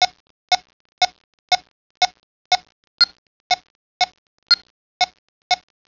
korg-lma120.wav